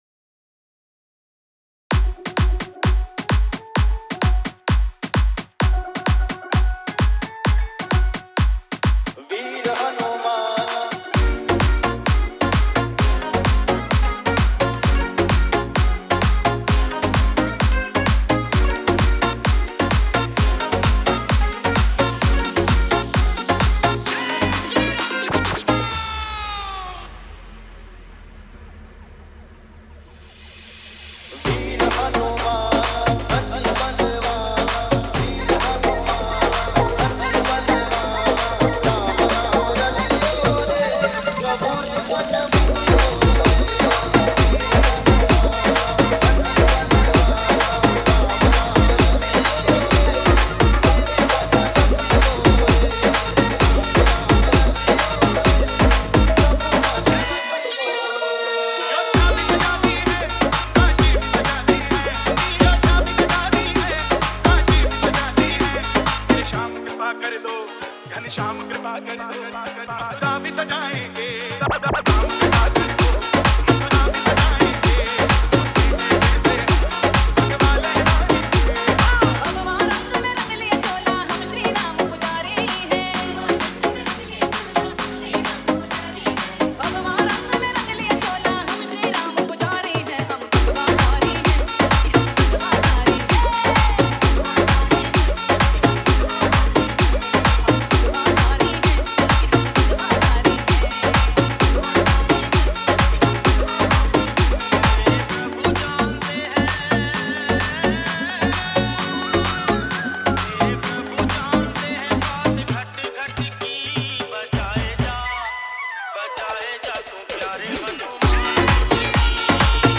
Category : Ram Navami Special Dj